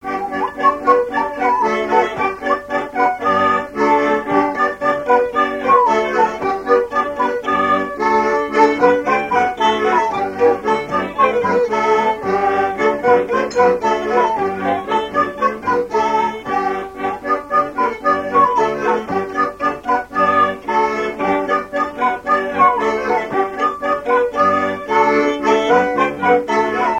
Couplets à danser
branle : courante, maraîchine
Pièce musicale inédite